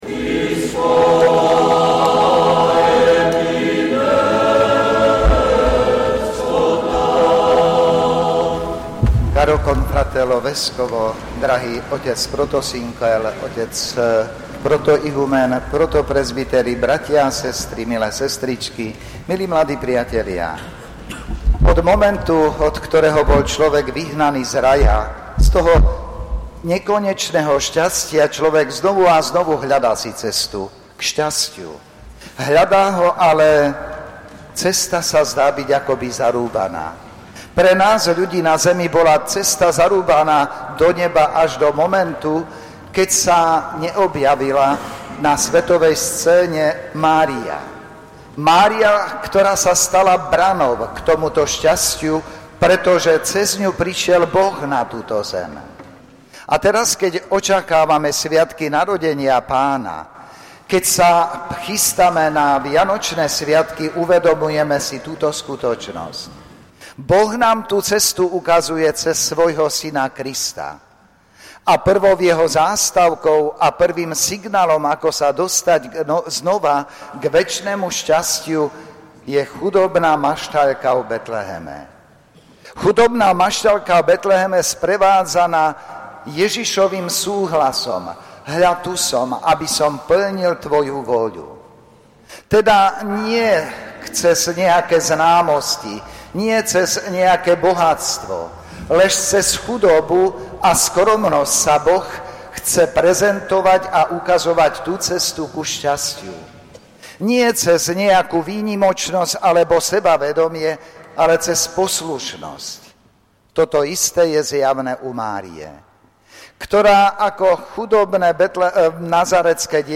V prvú decembrovú sobotu sa vladyka Milan Chautur CsSR, košický eparcha, zúčastnil na fatimskej sobote v Bazilike minor Zoslania Svätého Ducha v Michalovciach.
Vo svojej kázni vladyka Milan upriamil pozornosť veriacich na Bohorodičku, ktorá otvorila cestu do neba svojím súhlasom Kristovho príchodu na svet. Takto sa stala vzorom dokonalého správania a jej syn Ježiš Kristus zdrojom sily pre každého, kto takto chce konať.